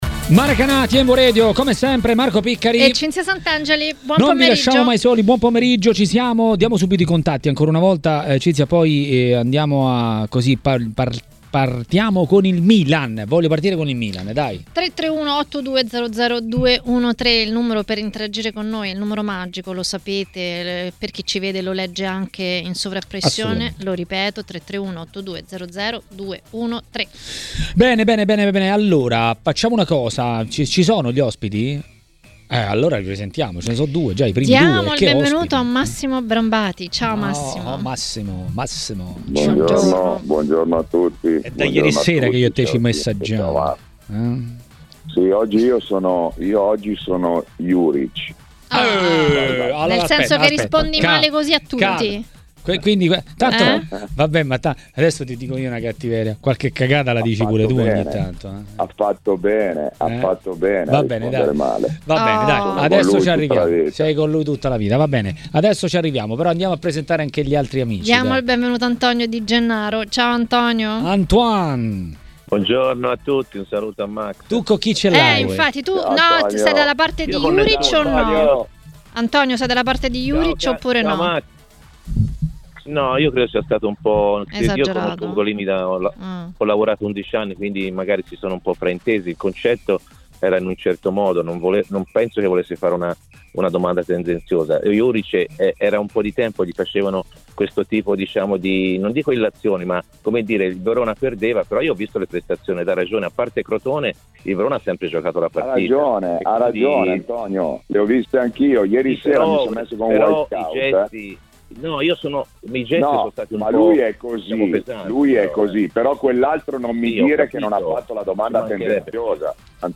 A parlare del campionato a TMW Radio, durante Maracanà, è stato l'ex calciatore Roberto Tricella.